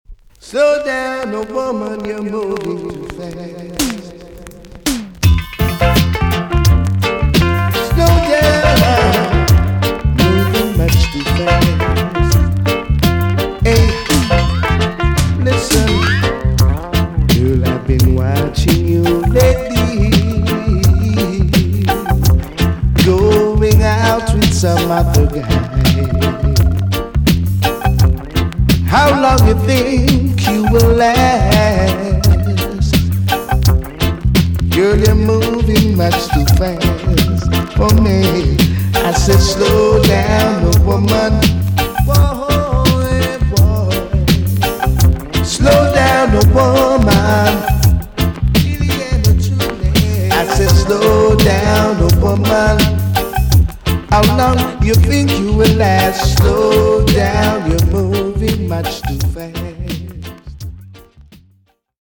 TOP >DISCO45 >80'S 90'S DANCEHALL
EX-~VG+ 少し軽いチリノイズが入ります。
1985 , US , NICE VOCAL TUNE!!